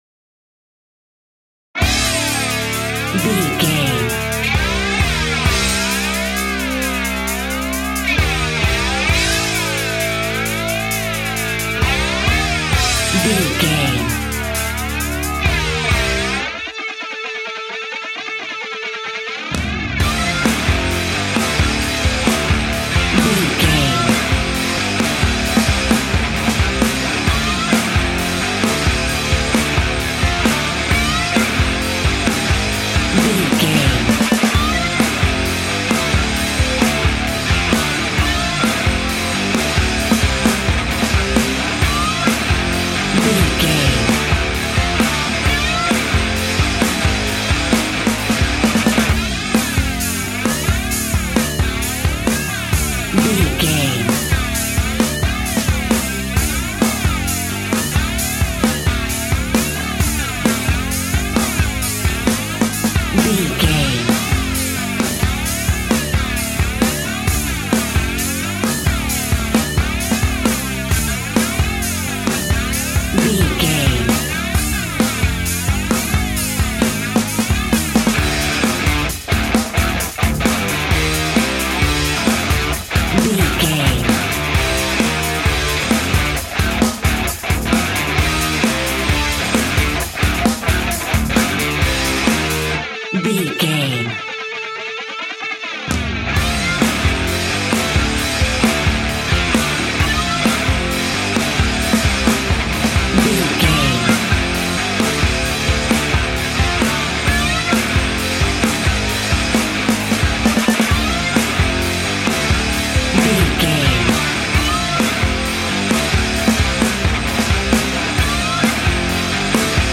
Epic / Action
Aeolian/Minor
hard rock
guitars
heavy metal
horror rock
rock instrumentals
Heavy Metal Guitars
Metal Drums
Heavy Bass Guitars